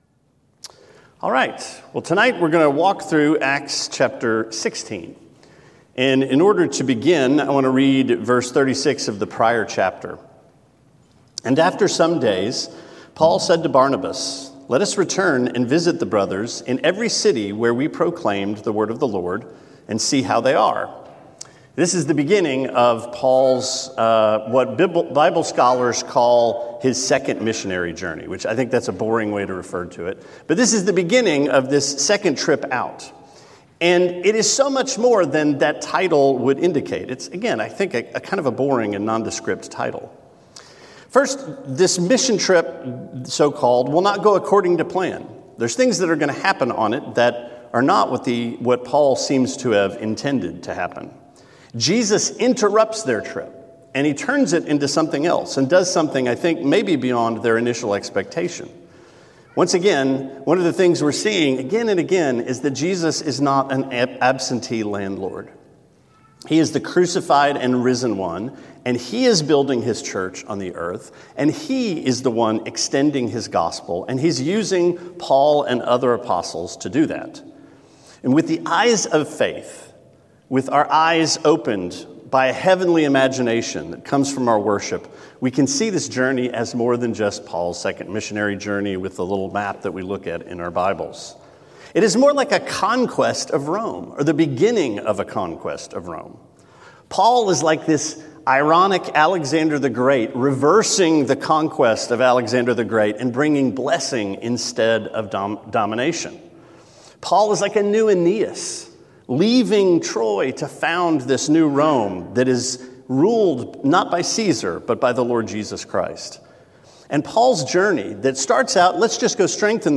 Sermon 1/30: Acts 16: Crumblin’ Down